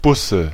Ääntäminen
Vaihtoehtoiset kirjoitusmuodot Buße Ääntäminen Tuntematon aksentti: IPA: /ˈbʊsə/ Haettu sana löytyi näillä lähdekielillä: saksa Käännöksiä ei löytynyt valitulle kohdekielelle.